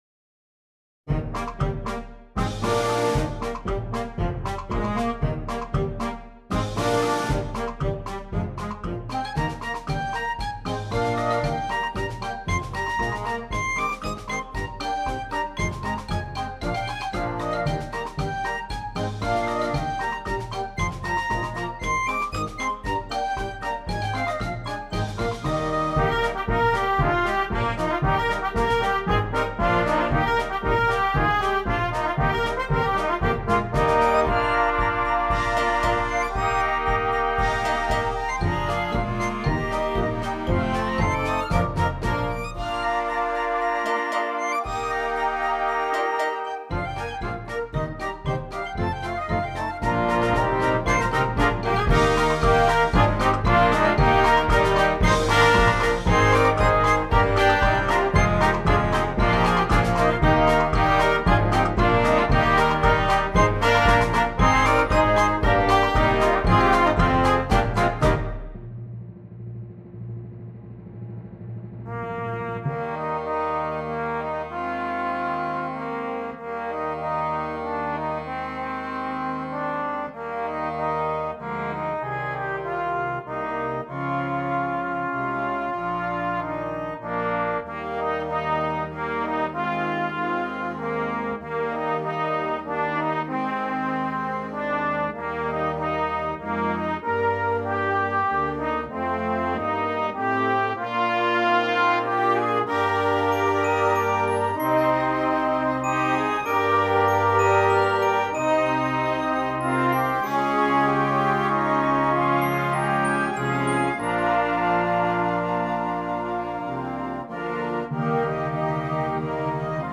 Concert Band
concert band piece